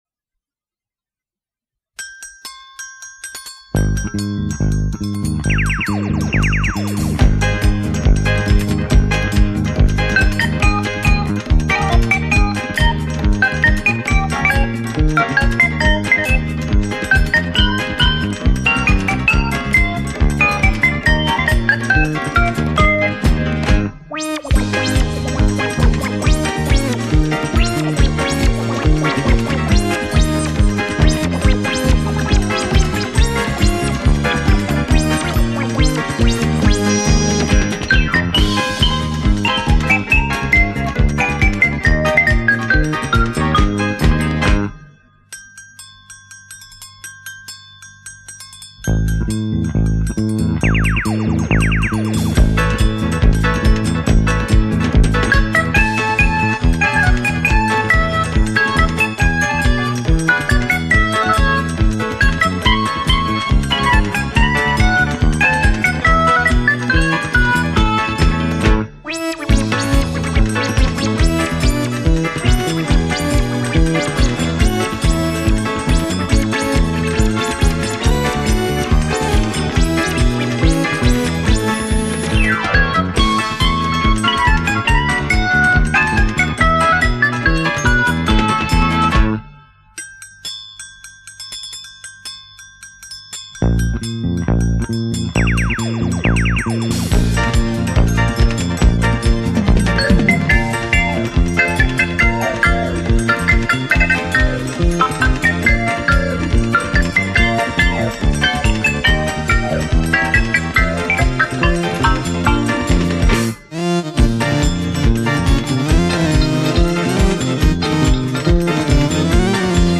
资　源：磁带转 MP3  　 .